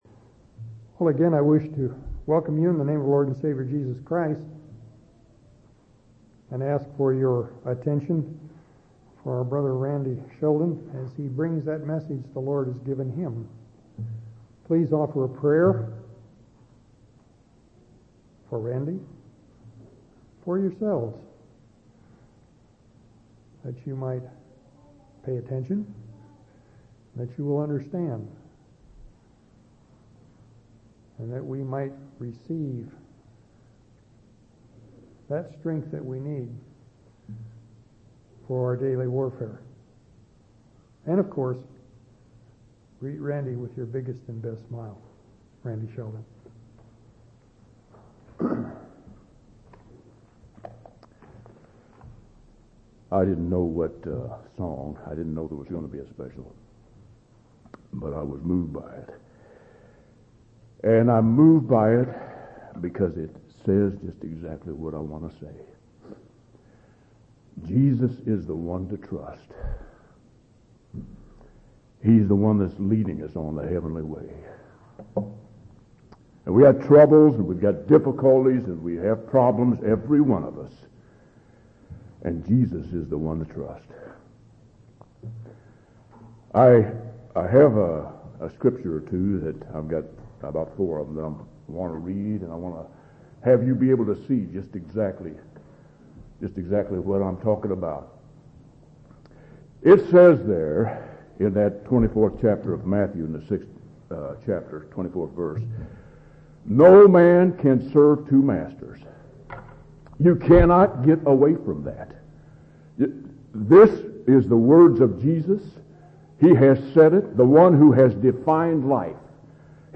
8/17/2003 Location: Temple Lot Local Event